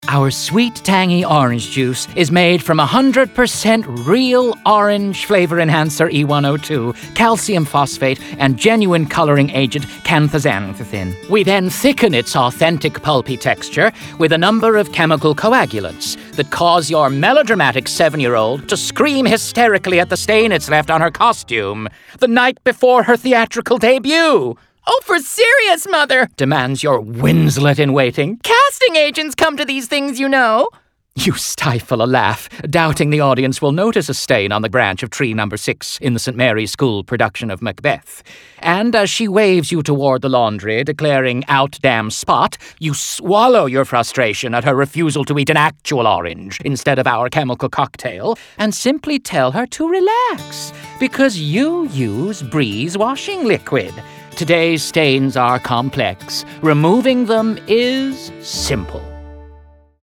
Radio Commercials
(Theatrical American Accent / Nathan Lane type)